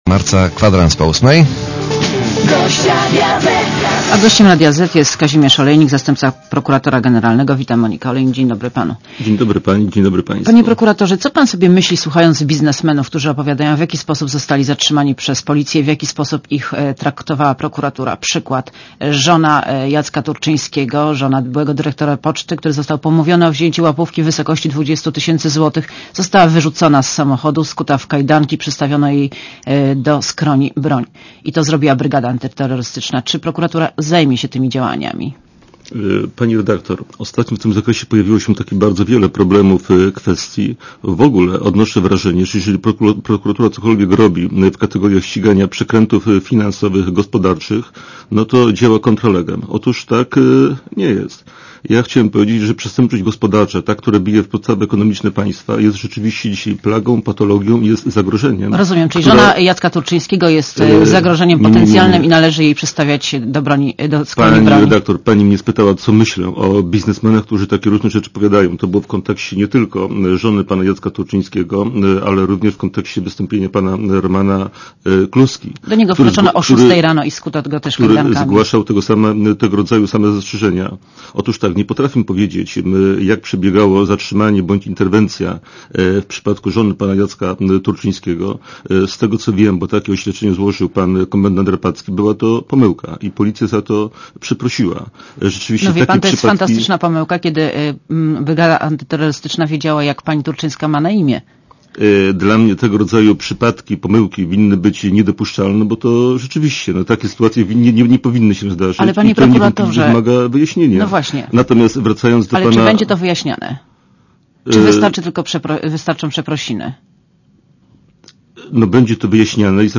Monika Olejnik rozmawia z zastępcą prokuratora generalnego Kazimierzem Olejnikiem